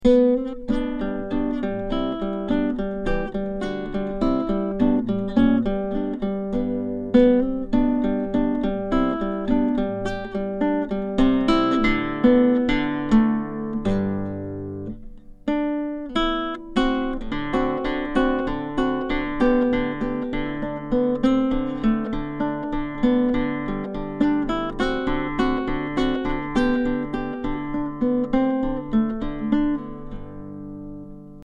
Ashington Folk Club - Spotlight 16 November 2006
transducer mic
classical guitar